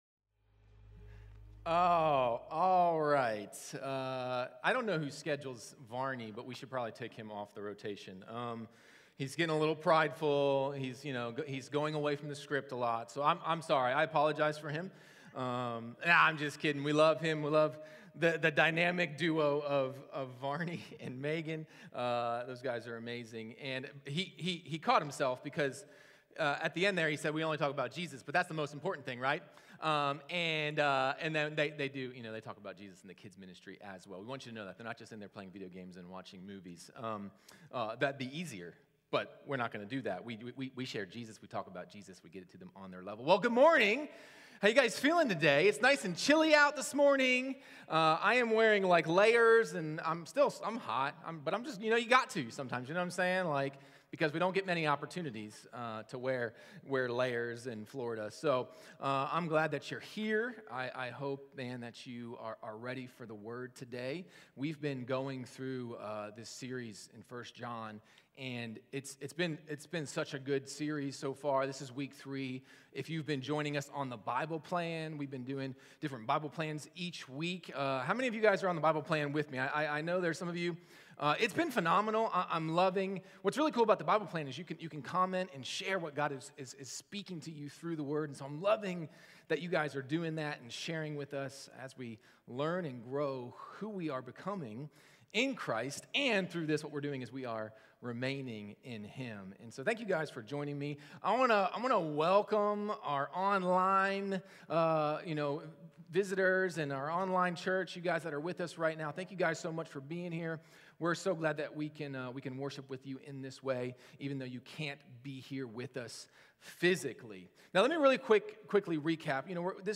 Sermons | Kairos Church